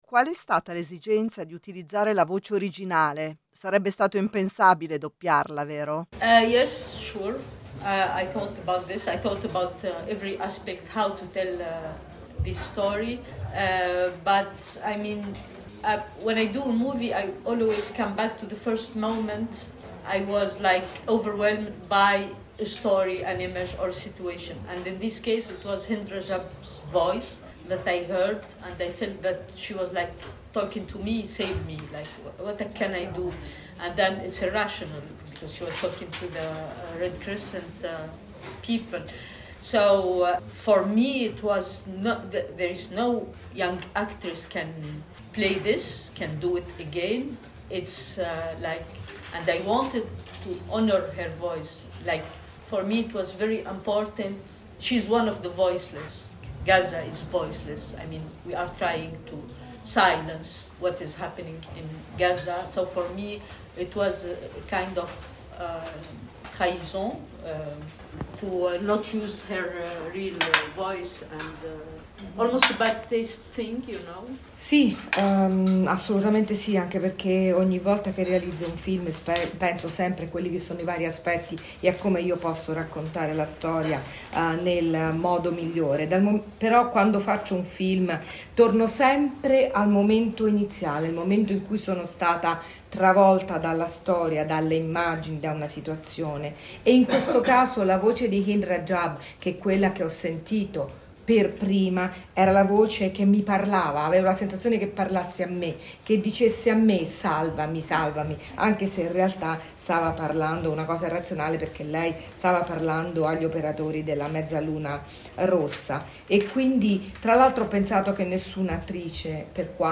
In onda nella trasmissione Chassis del 13/9/2025.